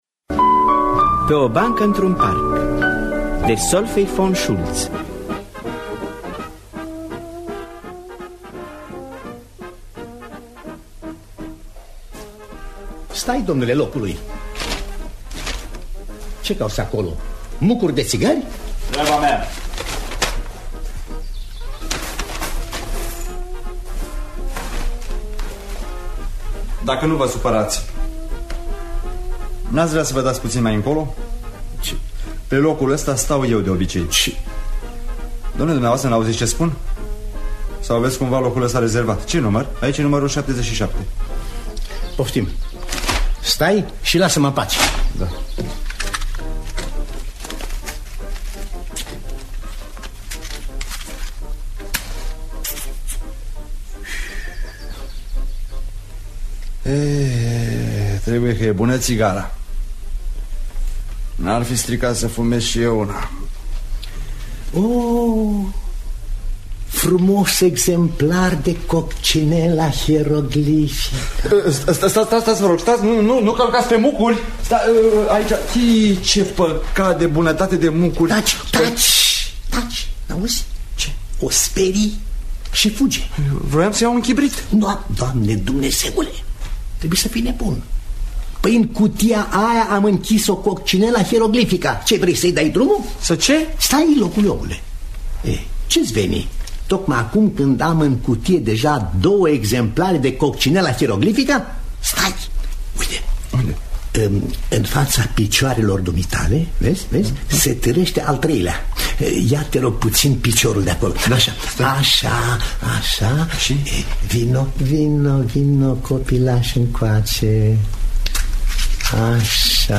Adaptare radiofonica dupa nuvela „Parkbänk” de Solveig von Schoultz.